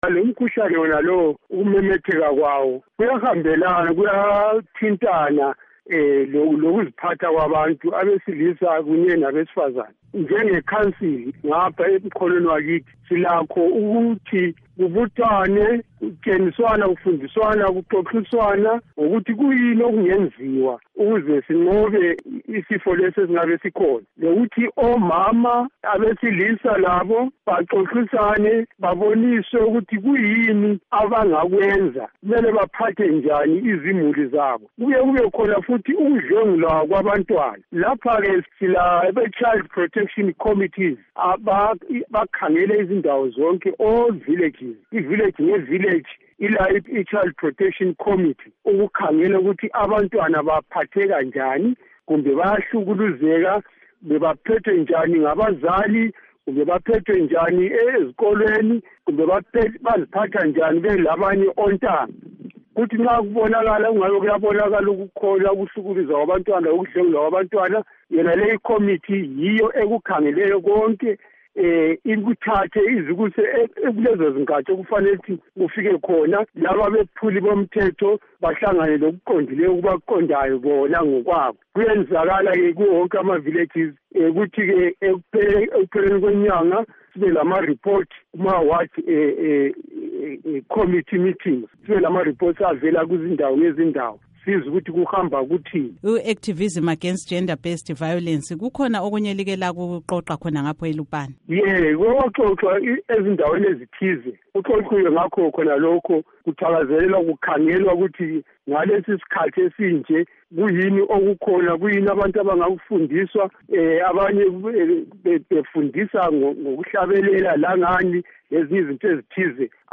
Ingxoxo LoKhansila Esau Sibanda